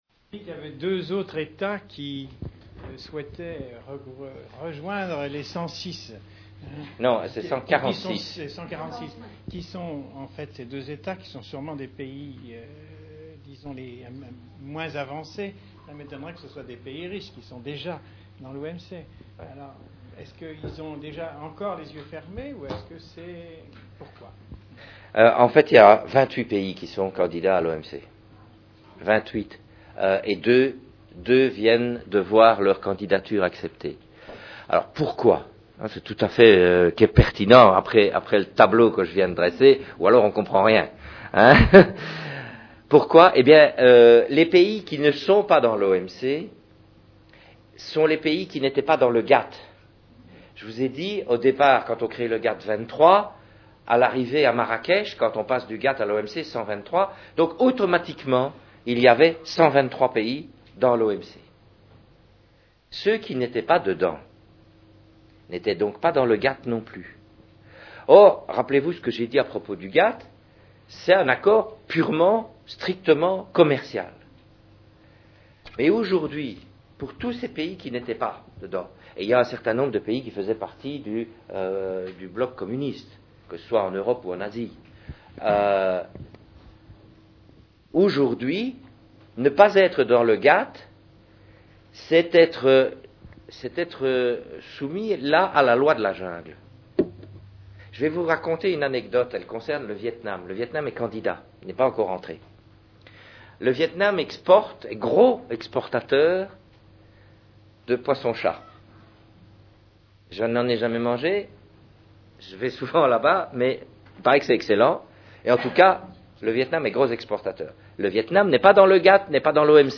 Conférence au Théâtre de la Colline, Paris, septembre 2003
II - Les "Questions-Réponses"